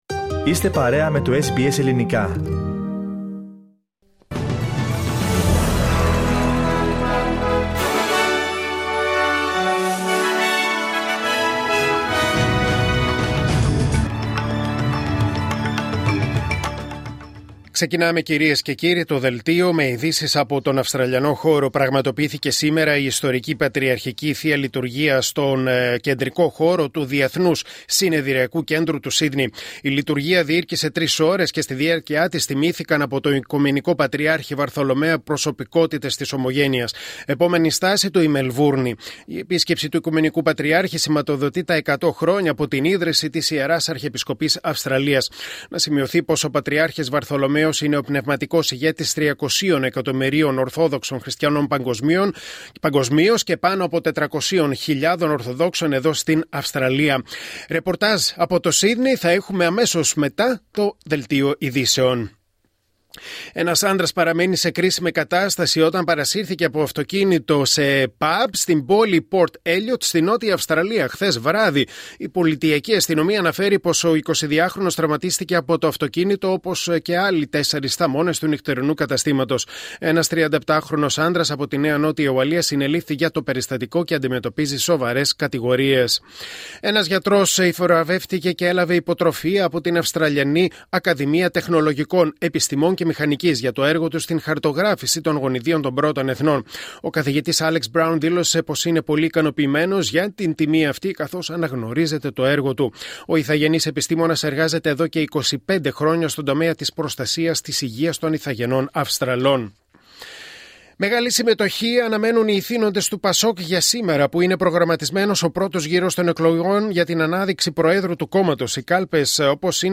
Δελτίο Ειδήσεων Κυριακή 06 Οκτωβρίου 2024